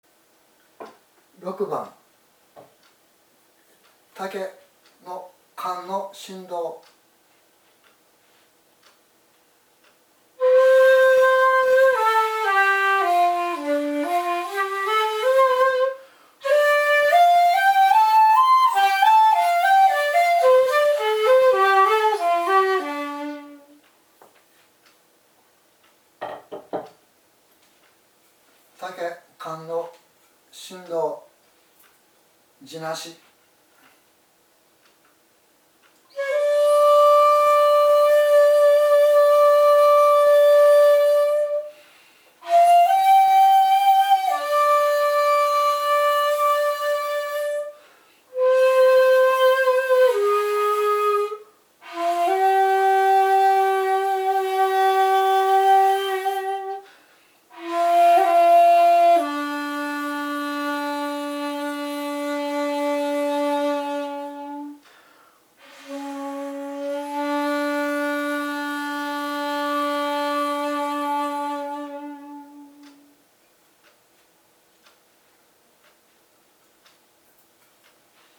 今回は如何に地無し管で琴古流本曲が生き返ってくるか、を地塗り管と実際に吹き比べてみたいと思います。